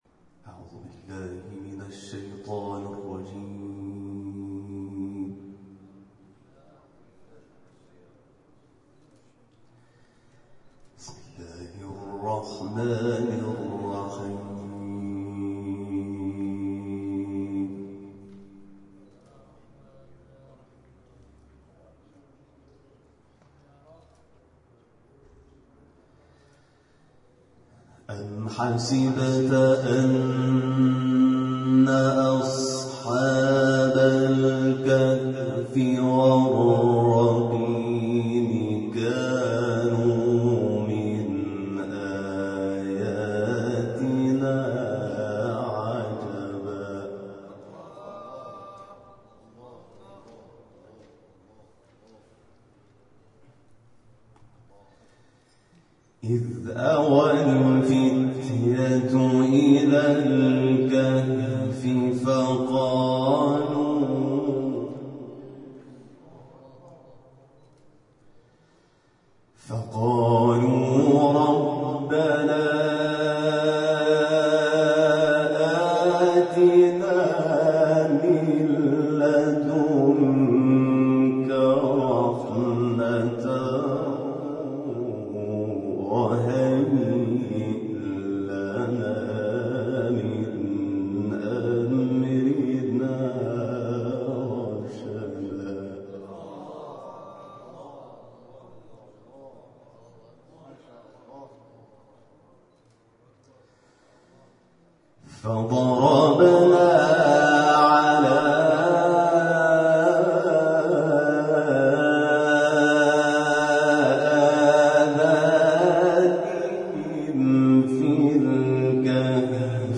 احیای عاشورایی با تلاوت قاریان بین المللی+صوت و عکس